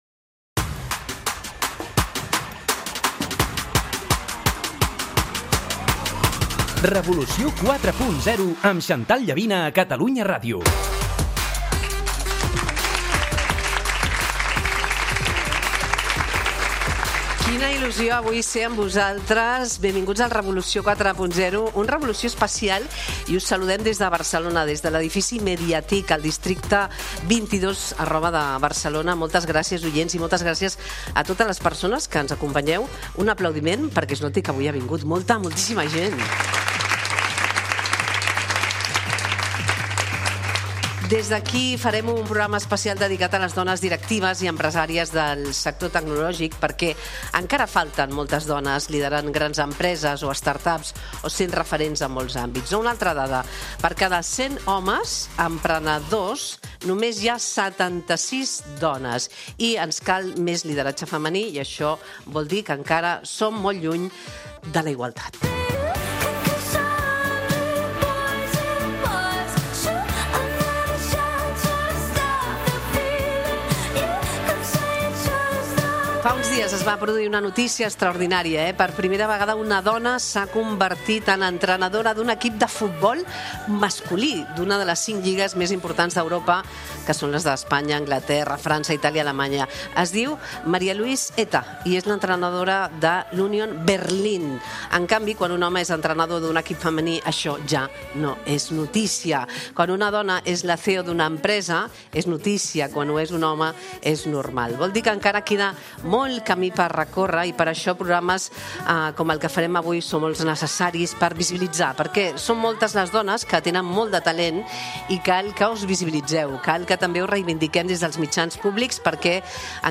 ofereix un programa especial enregistrat al MediaTIC dedicat al lideratge femení en tecnologia i emprenedoria.